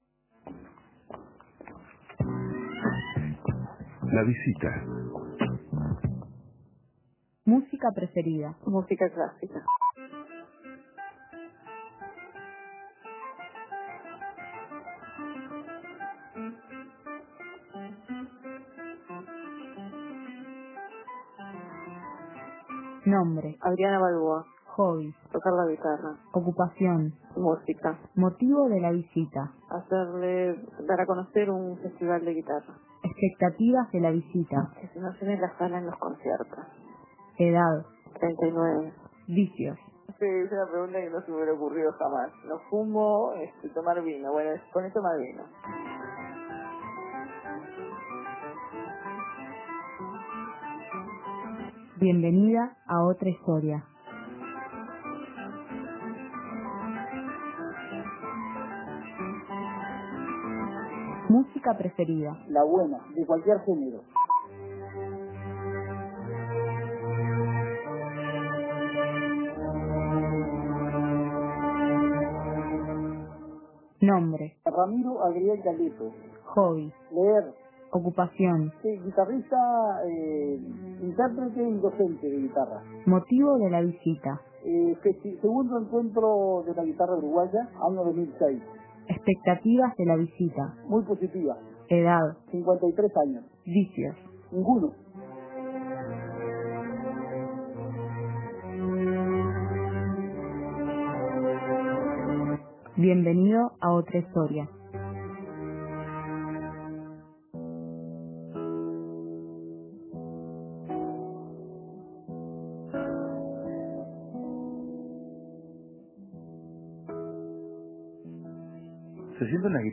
En esta ocasión el set se enfoca exclusivamente en la música latinoamericana. Escuche la entrevista y parte del repertorio de estos grandes instrumentistas.